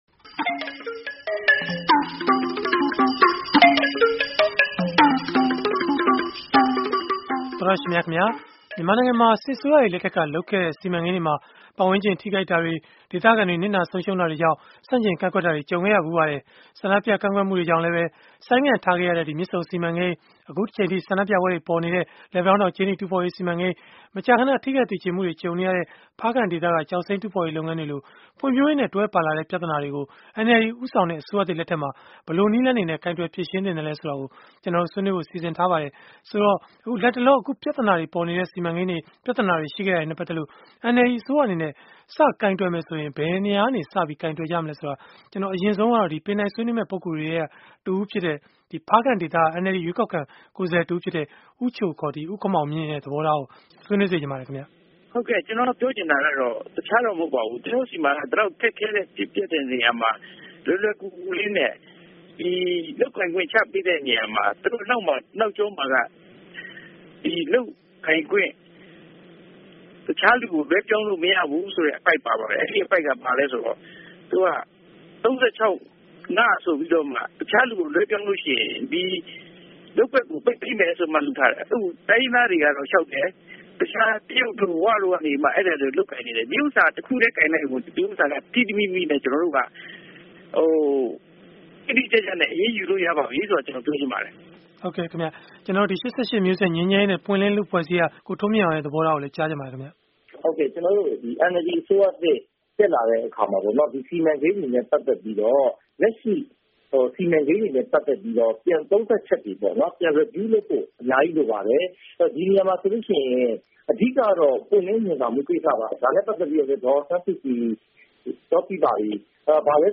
ဆန္ဒပြကန့်ကွက်မှုတွေ ကြုံခဲ့ရတဲ စစ်အစိုးရတွေ လက်ထက်က စီမံကိန်းတွေလို ဖွံ့ဖြိုးရေးနဲ့ တွဲပါလာတဲ့ ပြဿနာတွေကို NLD ဦးဆောင်တဲ့ အစိုးရသစ် လက်ထက်မှာ ဘယ်လိုကိုင်တွယ်ဖြေရှင်းမလဲ ဆိုတာကို အင်္ဂါနေ့ တိုက်ရိုက်လေလှိုင်း အစီအစဉ်မှာ ဆွေးနွေးထားပါတယ်။